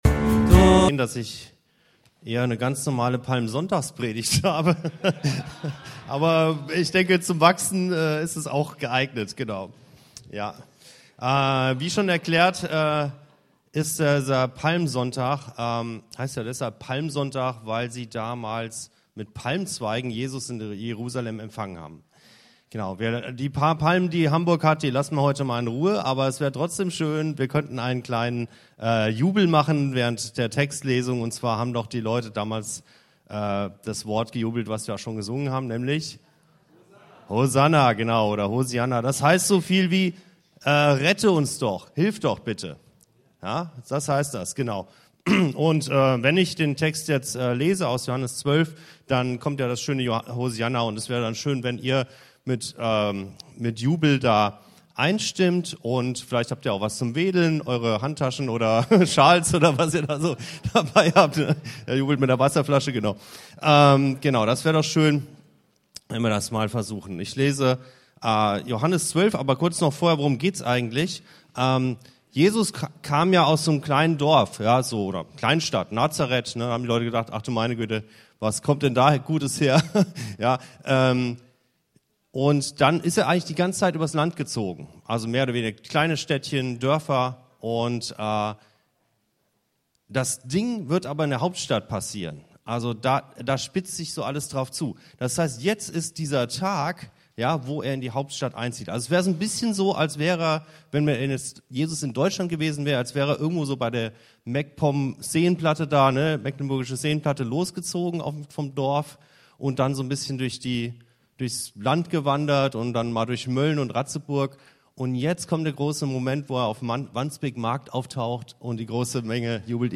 Jesus kommt als wahrer König – demütig, friedvoll, auf einem jungen Esel. Er erfüllt Gottes Königsgesetz: keine Pferde, kein Harem, kein Gold – stattdessen Hingabe bis ans Kreuz. Diese Predigt lädt ein, falsche Könige zu entthronen und Jesu Königsherrschaft im Herzen zu empfangen – zur königlichen Würde der Kinder Gottes.